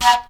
FLUTELIN14.wav